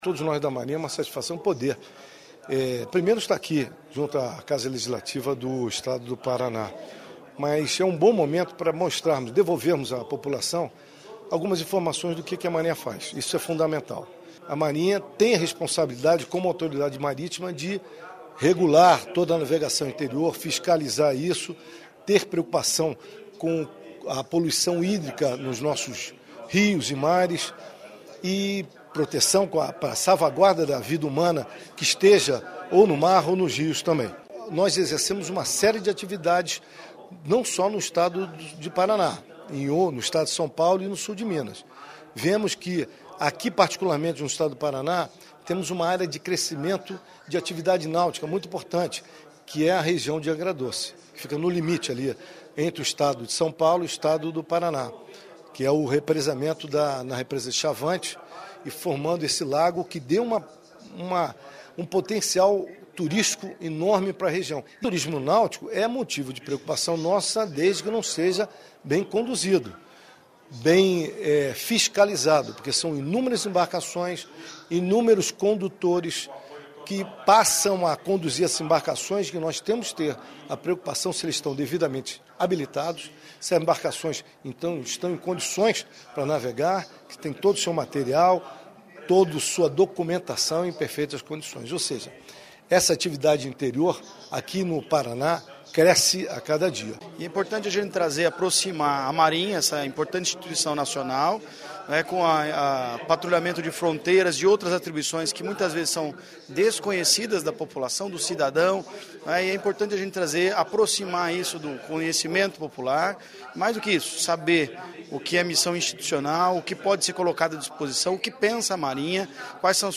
Ouça o representante da Marinha, o comandante do 8º Distrito Naval da Marinha, que responde pelo Paraná, o vice-almirante, Antônio carlos Soares Guerreiro e também o deputado Tião Medeiros, que trouxe o tema à Tribuna da Assembleia.
(sonoras)